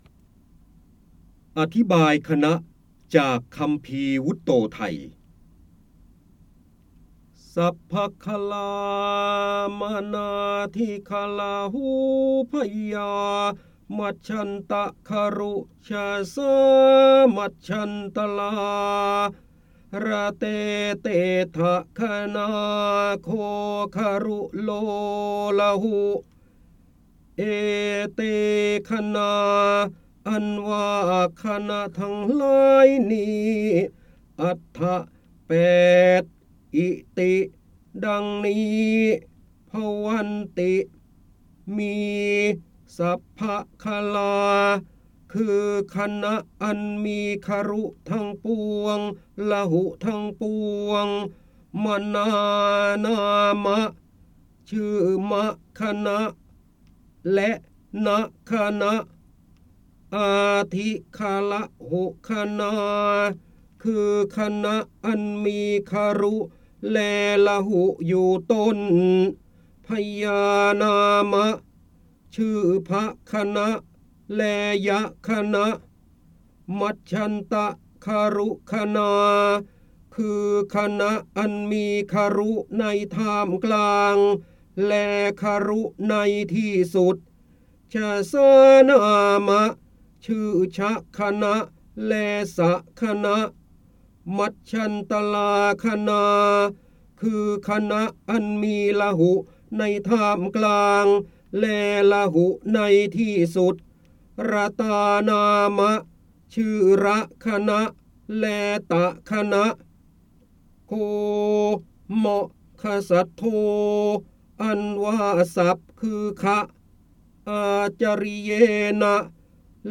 85 85 ตัวอย่าง ดาวน์โหลด ส่ง eCard เสียงบรรยายจากหนังสือ จินดามณี (พระโหราธิบดี) อธิบายคณะจากคำภีร์วุตโตทัย ได้รับใบอนุญาตภายใต้ ให้เผยแพร่-โดยต้องระบุที่มาแต่ห้ามดัดแปลงและห้ามใช้เพื่อการค้า 3.0 Thailand .